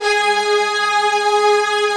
Index of /90_sSampleCDs/Optical Media International - Sonic Images Library/SI1_Fast Strings/SI1_Not Fast